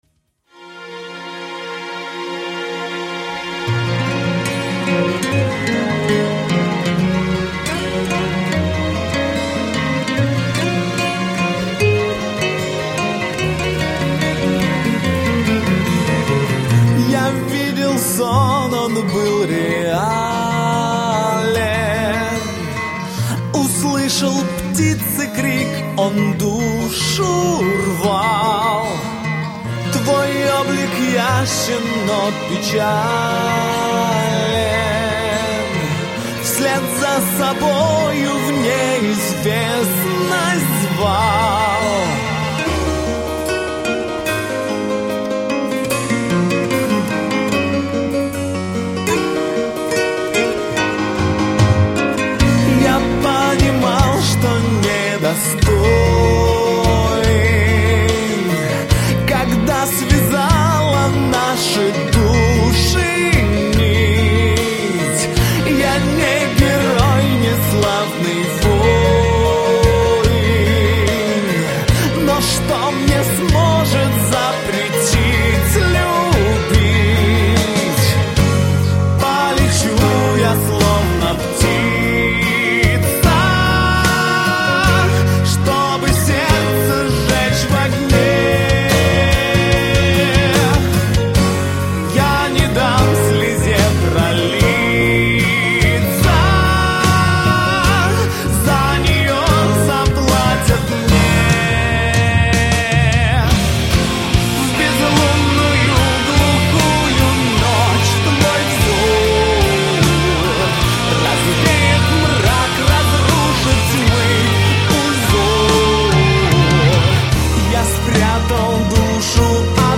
Хорошая, можно сказать спокойная композиция.